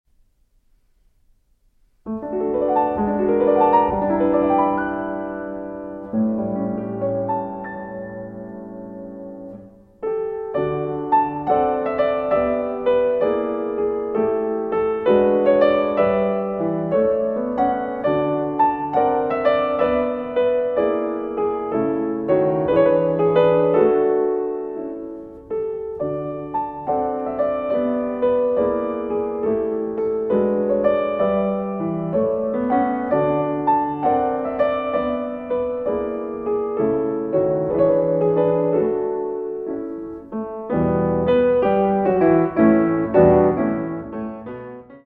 Vivo e leggiero, quasi improvisando